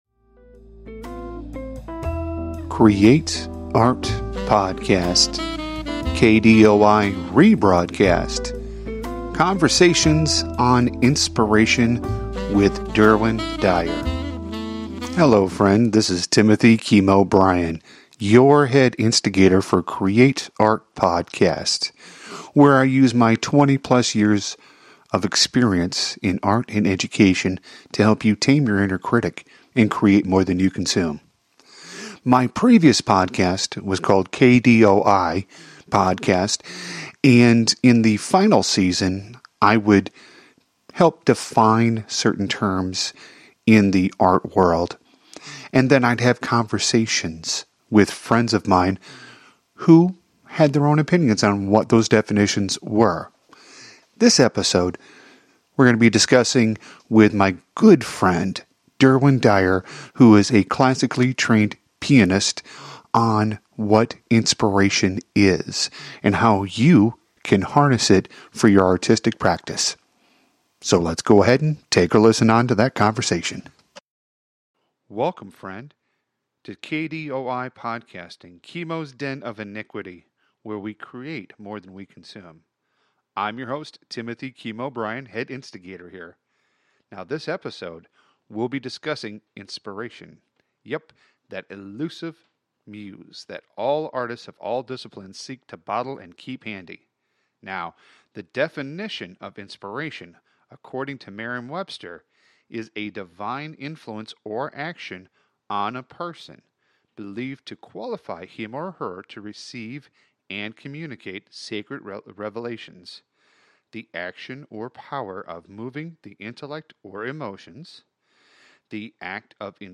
Have you ever really thought about what inspiration is and how to harness it? This is a rebroadcast from my old podcast during its final season where I talked about terms in the art world and had a conversation with friends. This episode deals with inspiration and how we define it and harness it in our practice.